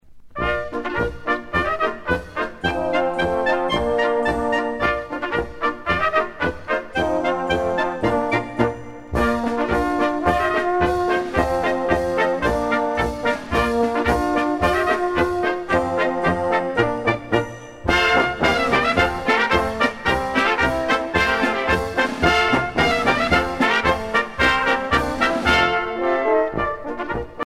danse : marche ; danse : polka ;
Pièce musicale éditée